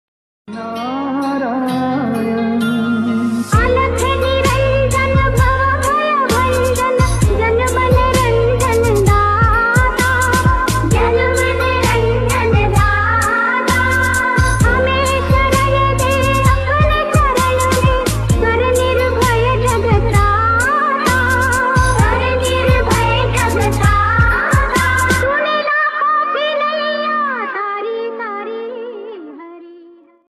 bhakti ringtone mobile | trending remix ringtone download